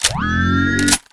reload.ogg